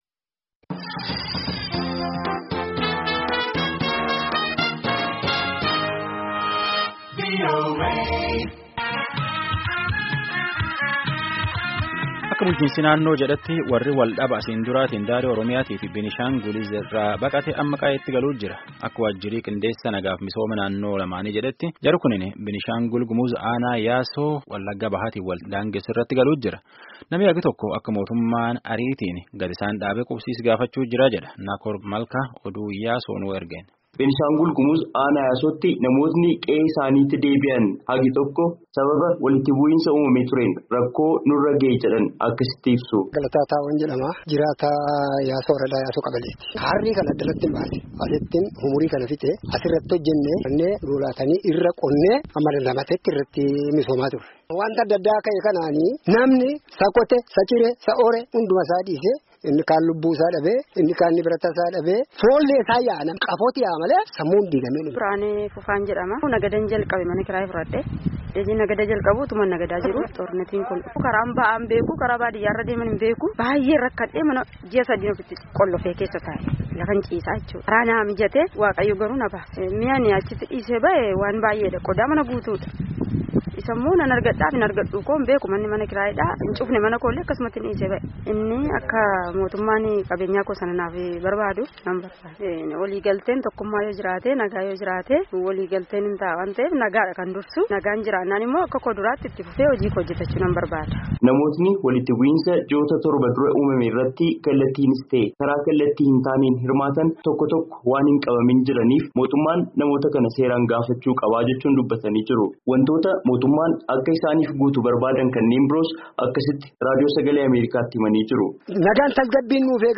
Yaasoorraa gabaasaa qaba